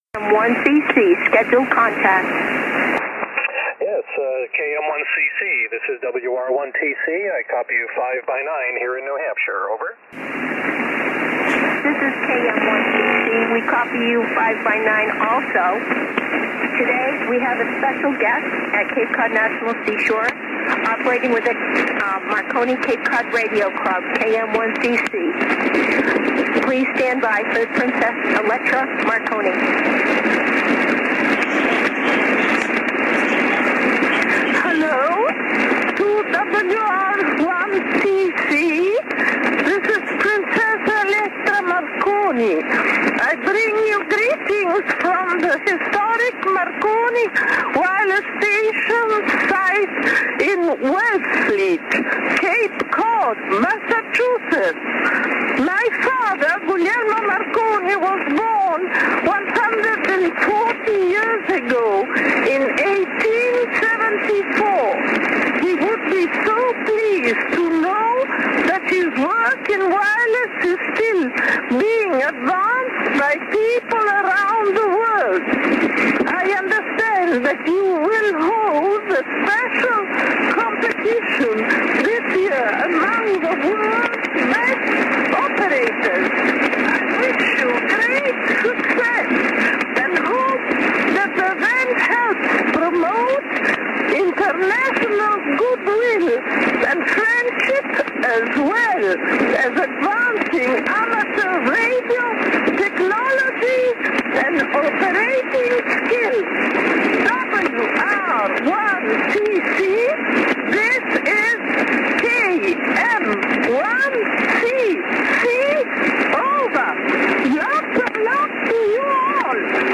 The contact occurred on on 40 M 7.150 SSB at 2:30pm EDST. Princess Marconi spoke about how her father would be proud that radio amateurs are still advancing his work in wireless communication. She went on to praise the WRTC2014 event and its ability to promote international goodwill and operating skill.